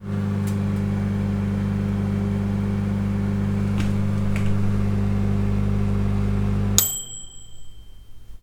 The ding
MicrowaveDing